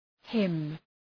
Προφορά
{hım}